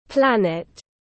Planet /ˈplæn.ɪt/